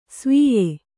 ♪ svīye